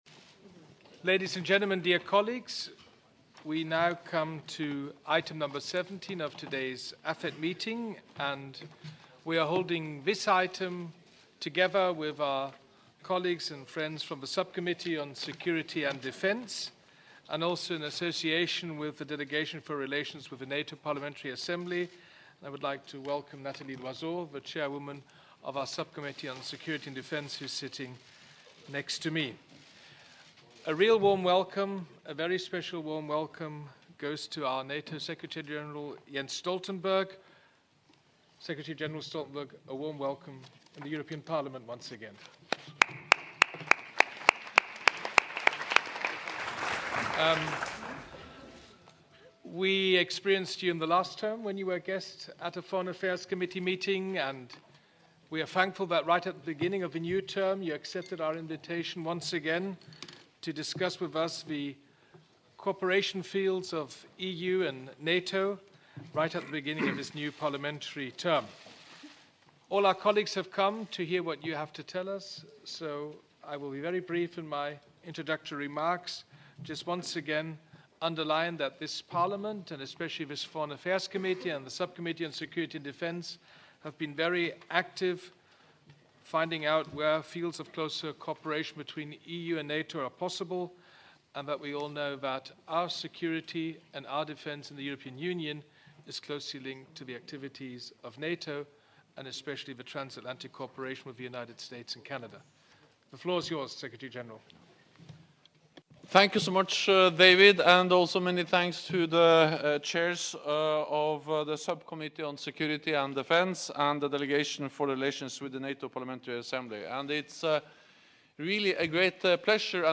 Addressing the European Parliament’s Foreign Affairs Committee and Sub-Committee on Security and Defence on Tuesday (21 January 2020), NATO Secretary General Jens Stoltenberg welcomed closer NATO-EU cooperation, including on maritime security, military mobility, and countering cyber and hybrid attacks.